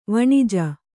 ♪ vaṇija